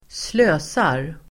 Uttal: [²sl'ö:sar]